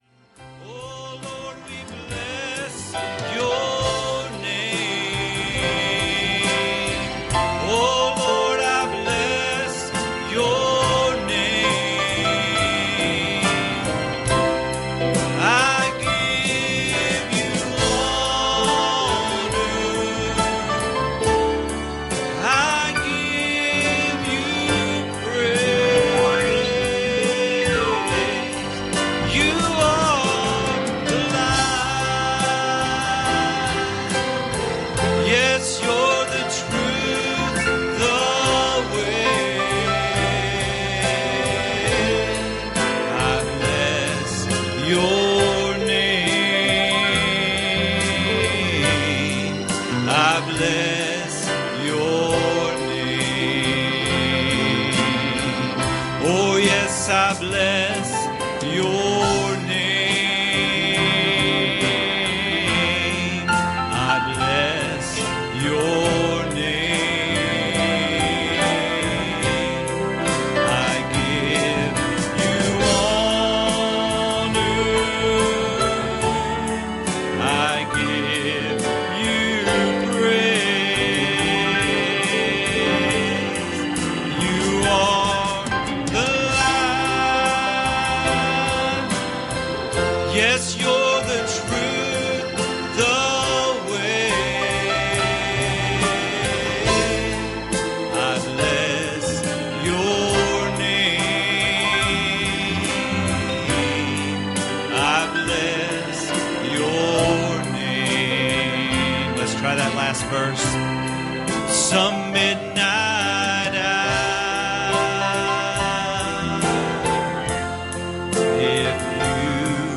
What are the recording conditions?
Passage: James 4:7 Service Type: Wednesday Evening